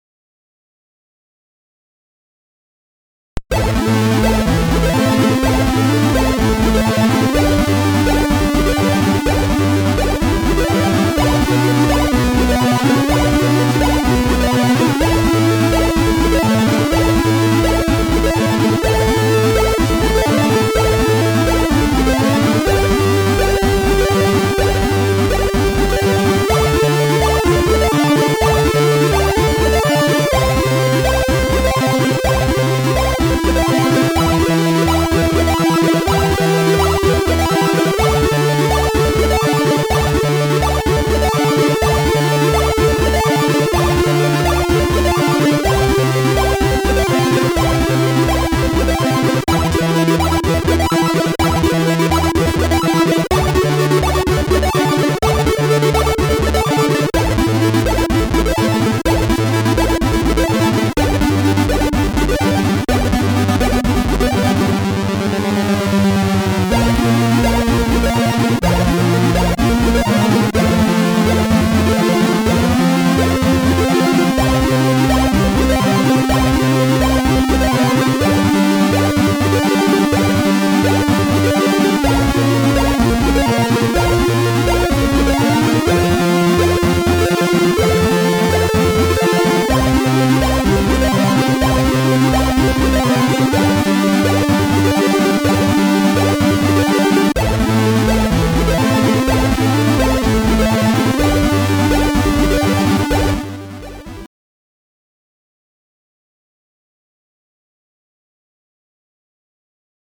SID Version: 8580 (PAL)